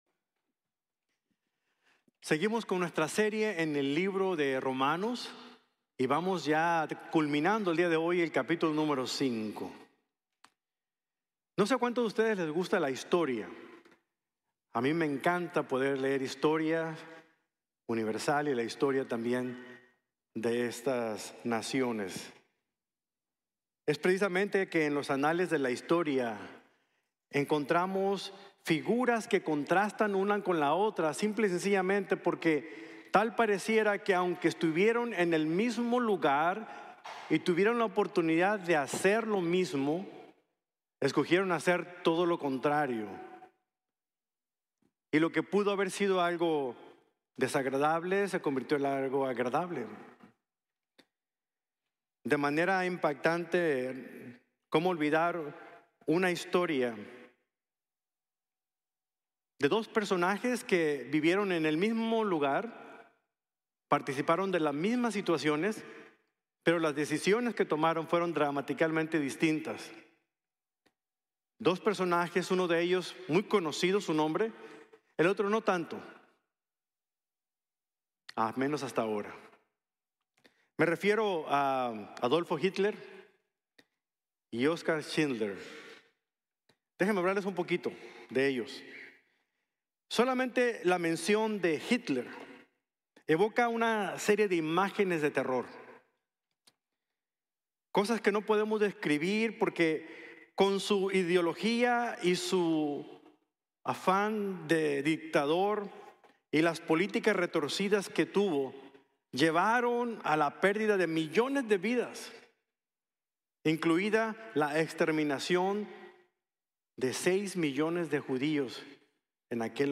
De la Caída al Favor | Sermon | Grace Bible Church